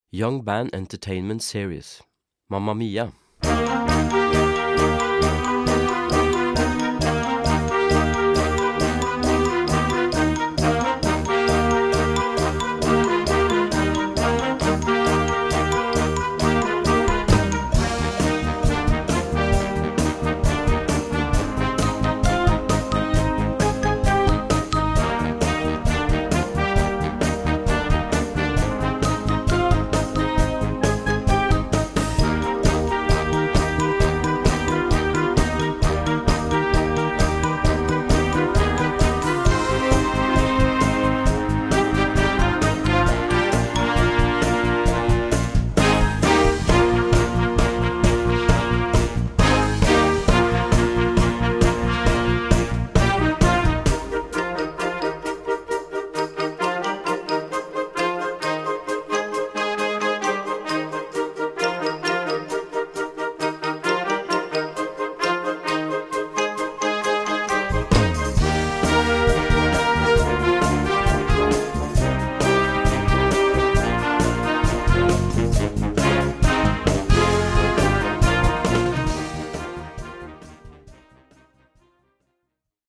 Gattung: Moderner Einzeltitel Jugendblasorchester
Besetzung: Blasorchester